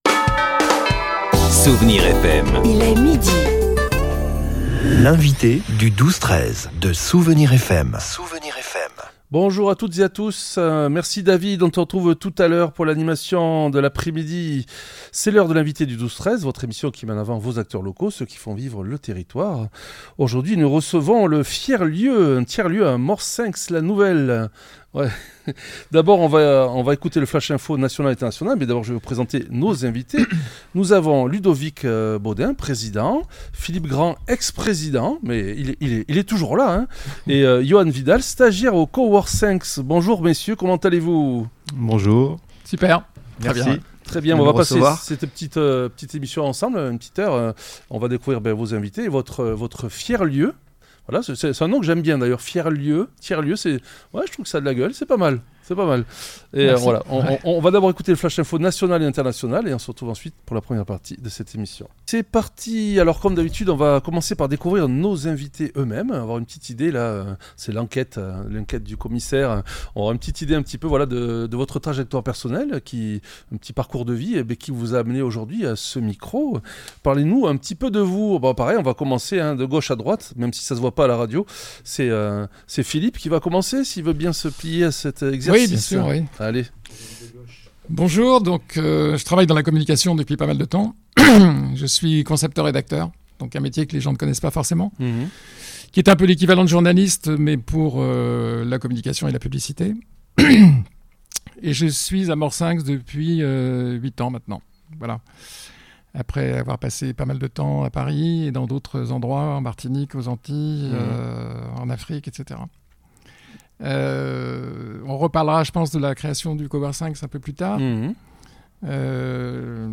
L’entretien a mis en lumière les ambitions de l’association pour 2026, notamment sa volonté de devenir une ressource locale de référence sur l'Intelligence Artificielle.